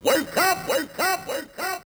VOX - WAKE UP.wav